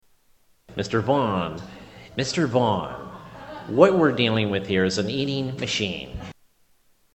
Tags: Comedians Darrell Hammond Darrell Hammond Impressions SNL Television